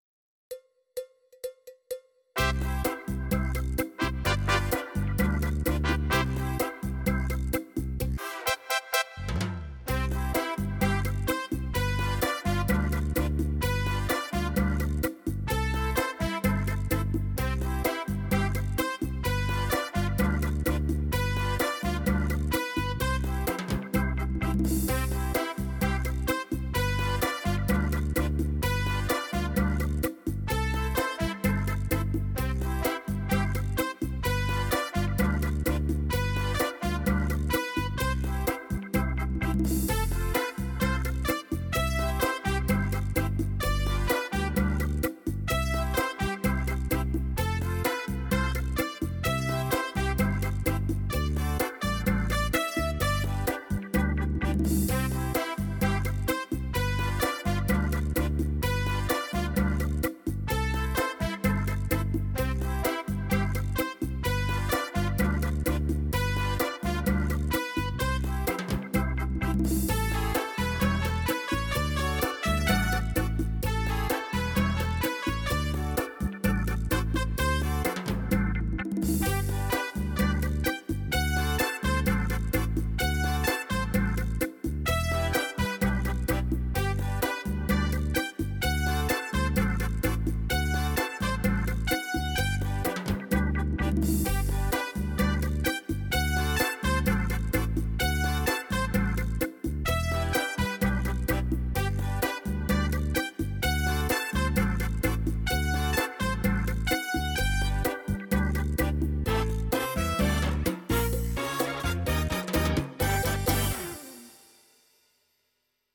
Cha Cha